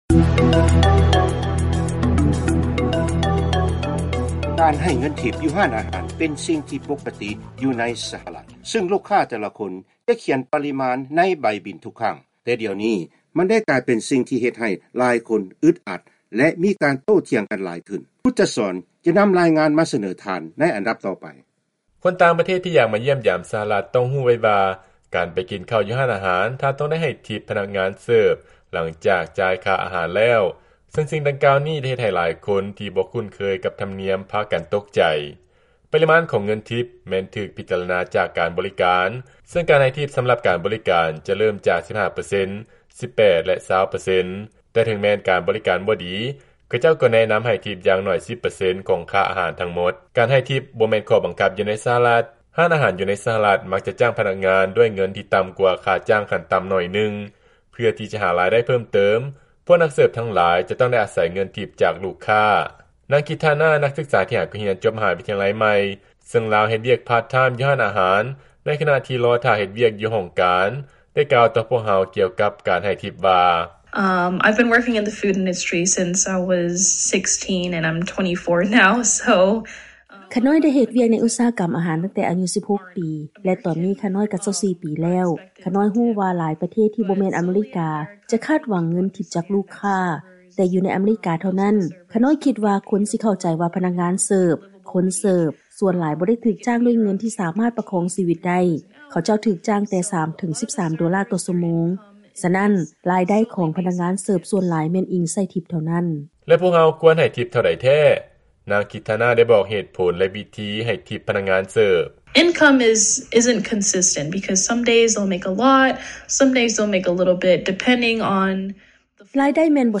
ຟັງລາຍງານ ການເກັບເງິນທິບຢູ່ຮ້ານອາຫານ ແລະ ການບໍລິການຕ່າງໆແມ່ນບັນຫາທີ່ກຳລັງມີການໂຕ້ຖຽງກັນຫຼາຍຂຶ້ນໃນ ສະຫະລັດ